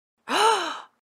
Звуки подарка
На этой странице собраны звуки подарков: от шелеста оберточной бумаги до радостных возгласов при вскрытии сюрприза.